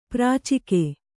♪ prācike